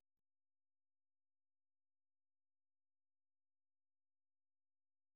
Форма симфоническая поэма
Произведение написано в тональности ре минор и является примером ранних тональных работ Шёнберга.